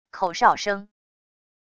口哨声wav音频